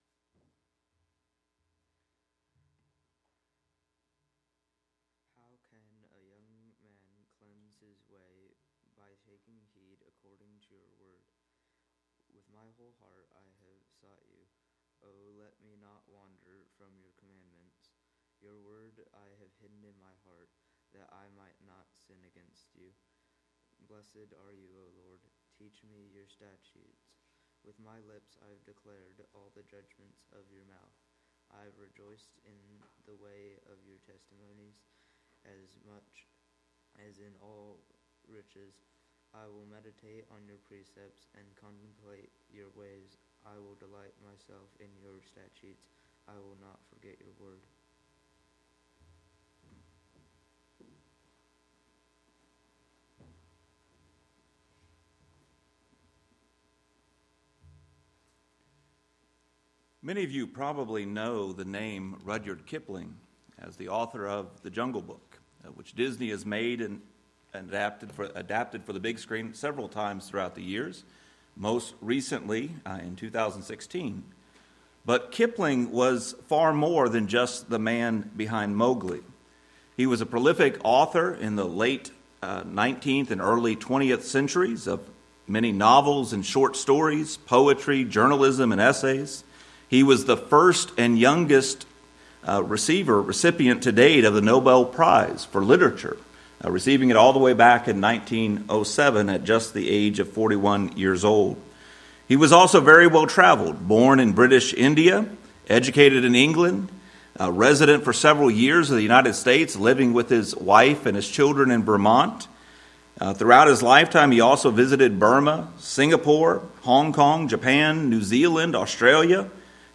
The goal of the sermon is to teach Christians how to structure daily devotional time with God.